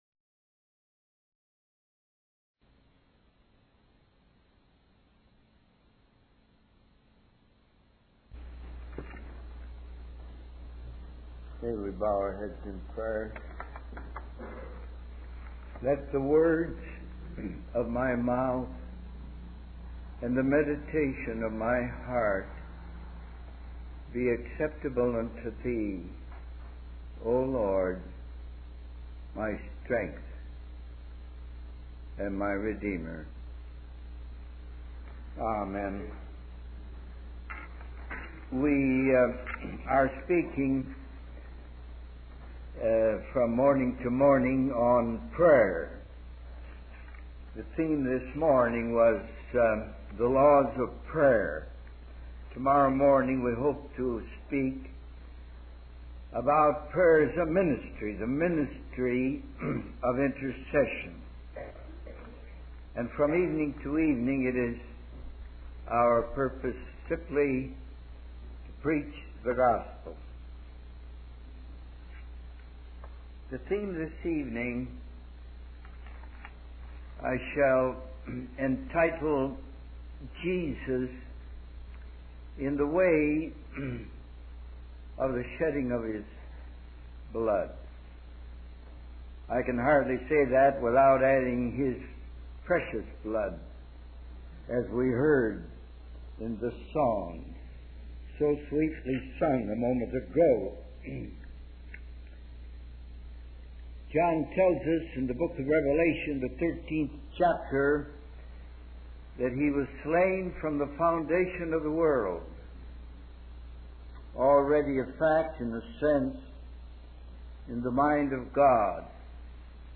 In this sermon, the speaker discusses his conversation with someone who reads Watchman Knee's book, 'Sit, Walk, Stand.'